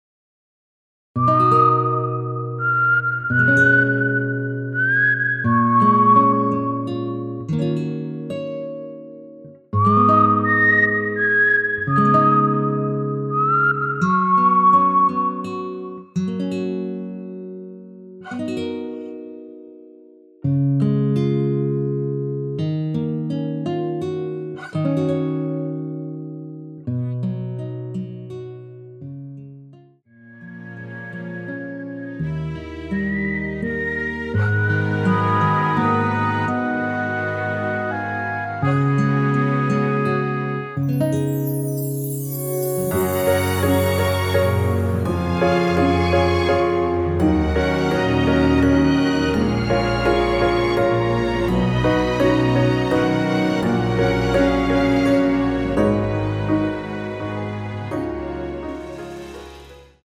무반주 구간 들어가는 부분과 박자 맞출수 있게 쉐이커로 박자 넣어 놓았습니다.(일반 MR 미리듣기 참조)
F#
◈ 곡명 옆 (-1)은 반음 내림, (+1)은 반음 올림 입니다.
휘파람 시작과 1절 후 후렴부분연결로 바로 이어져 축가에 더 편한 것 같아요.
앞부분30초, 뒷부분30초씩 편집해서 올려 드리고 있습니다.